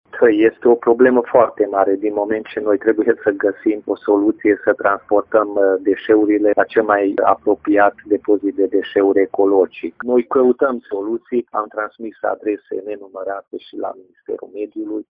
Problema deșeurilor este foarte gravă, spune viceprimarul municipiului Tîrgu-Mureș, Peti Andras, care spune că municipalitatea a făcut adrese la Minister pentru găsirea unei soluții: